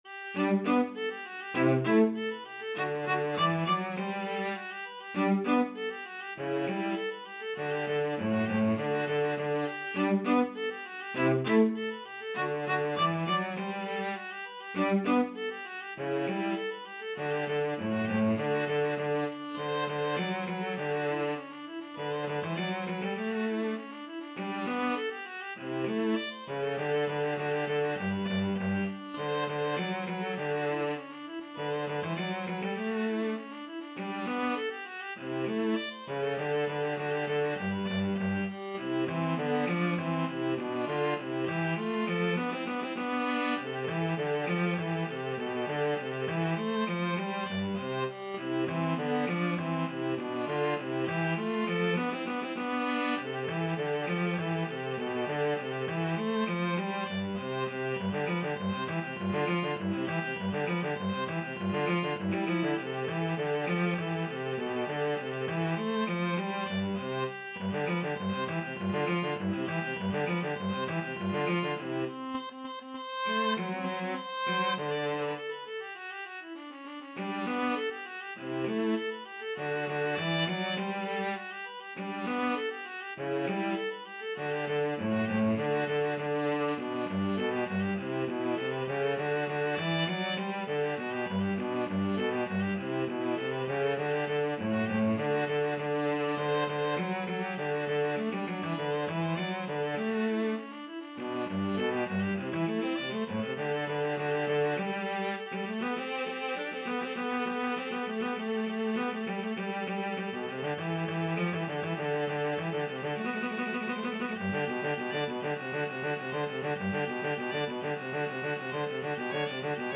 2/4 (View more 2/4 Music)
Classical (View more Classical Viola-Cello Duet Music)